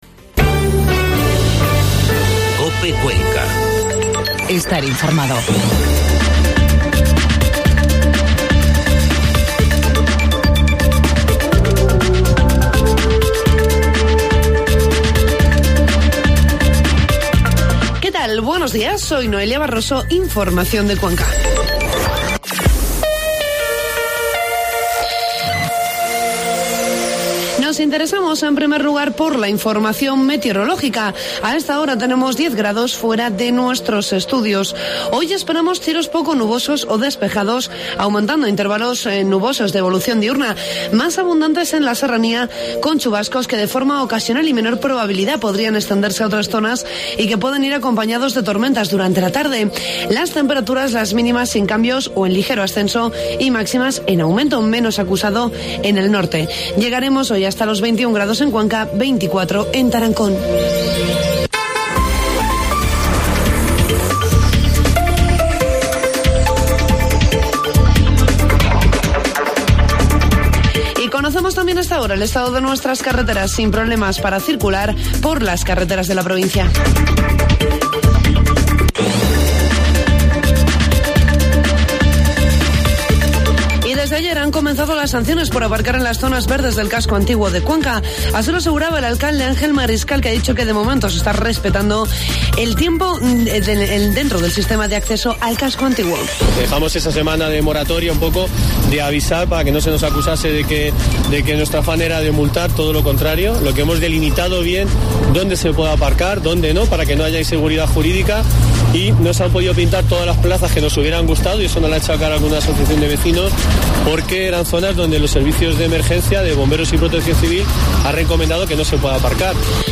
Informativo matinal COPE Cuenca 12 de junio